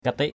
/ka-tɪʔ/ (d.) tên vua Champa (1433-1460)= nom d’un roi Campa. name of a Champa king.